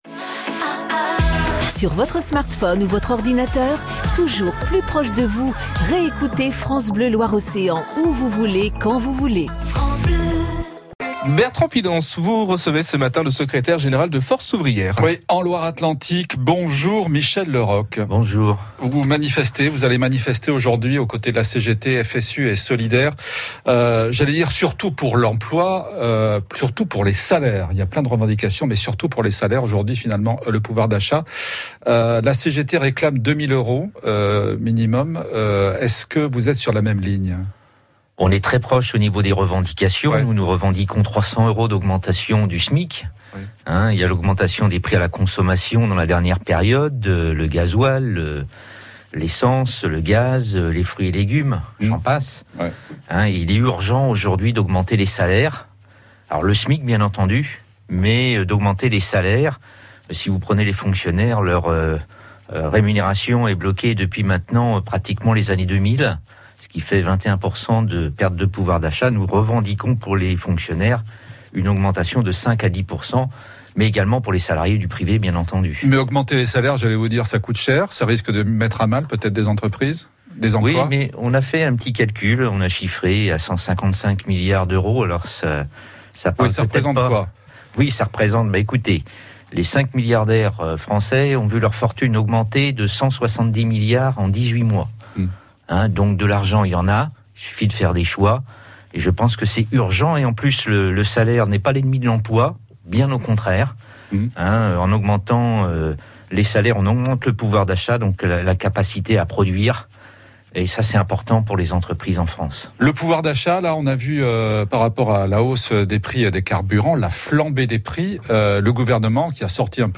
invité de France Bleu Loire Océan